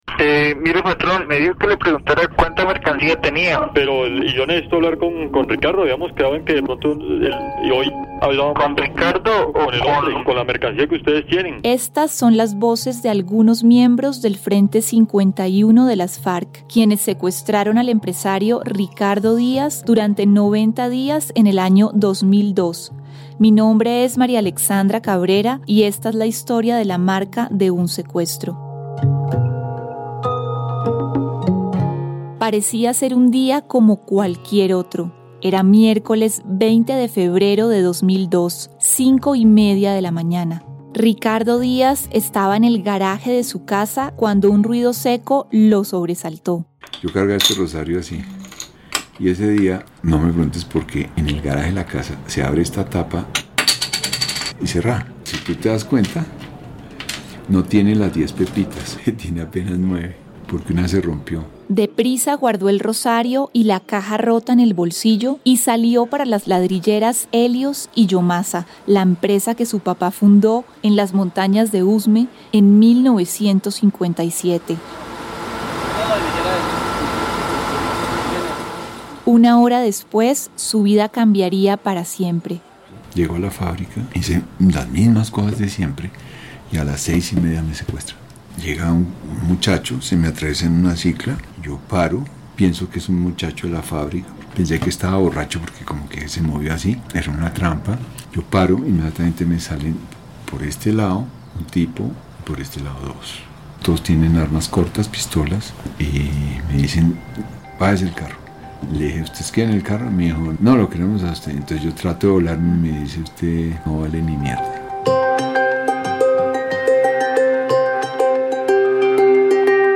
Serie radial basada en la publicación de 2019 del Centro Nacional de Memoria Histórica (CNMH): Voces que construyen : memorias de empresarios.